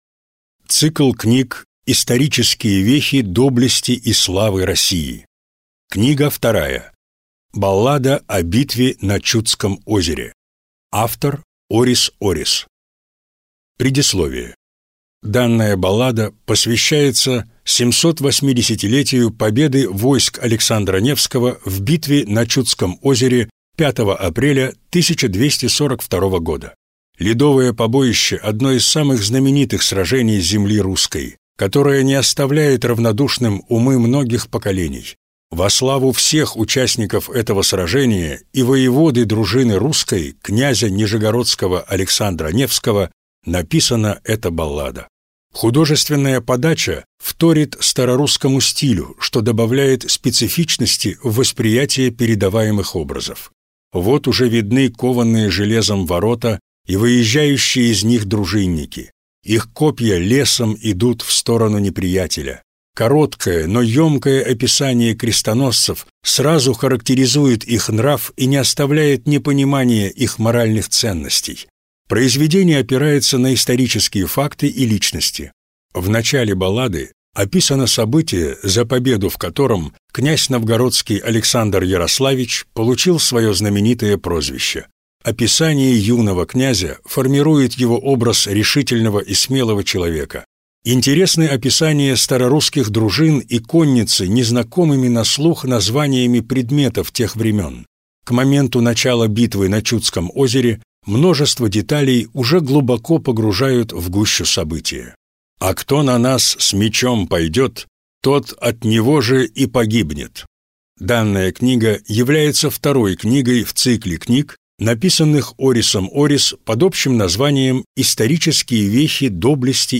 Аудиокнига Баллада о битве на Чудском озере | Библиотека аудиокниг